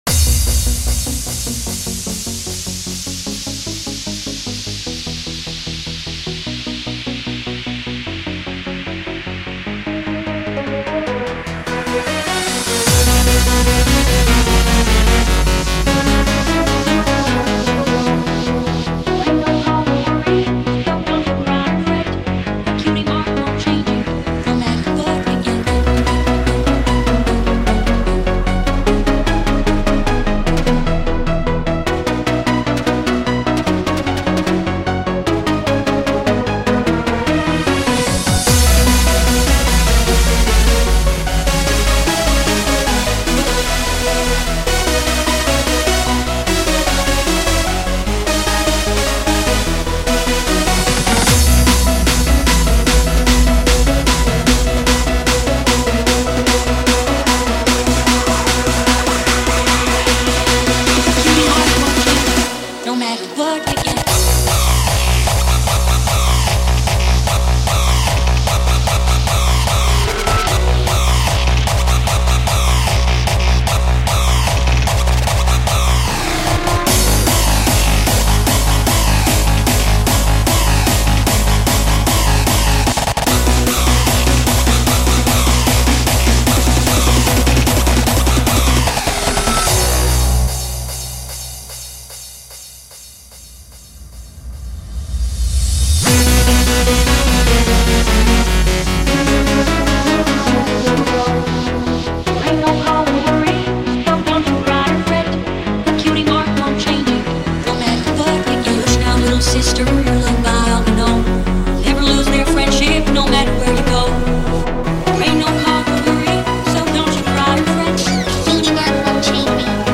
a Lullaby remix
MORE PONY HARDSTYLE!